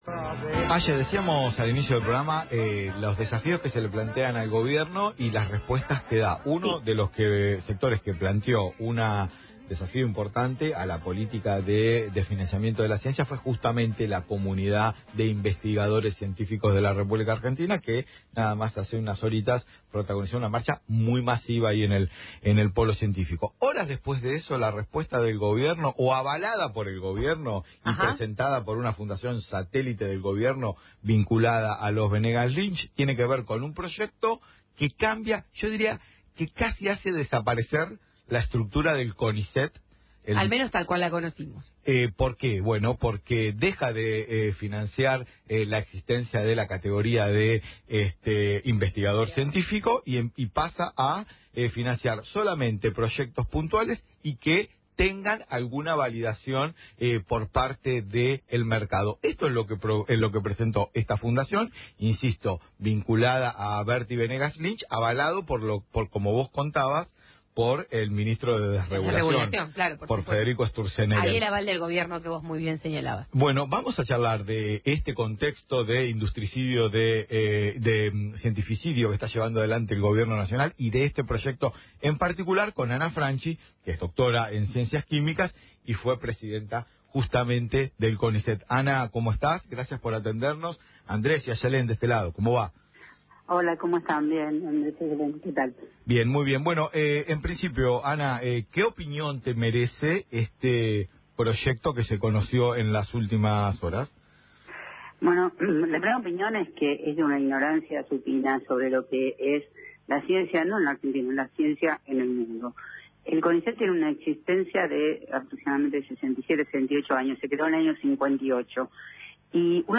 Ana Franchi, ex presidenta del CONICET, pasó por el aire de Es un Montón en Radio Provincia para hablar sobre las implicancias negativas que tendría ejecutar un proyecto de una fundación cercana al Gobierno para reformar el Conicet. El documento plantea eliminar la carrera del investigador y reemplazarla por contratos atados a proyectos de corto plazo.